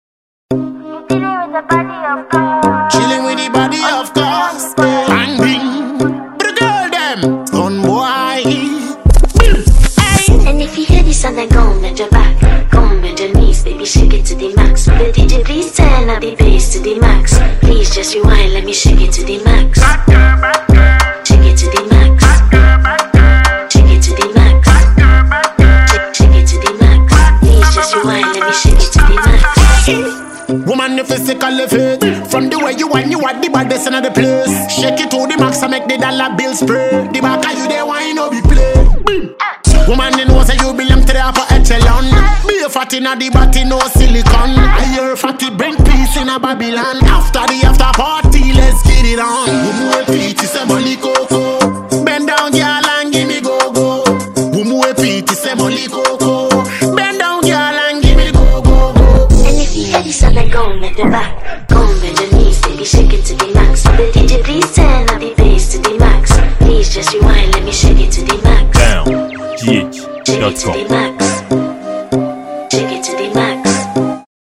Ghanaian afrobeat dancehall musician and songwriter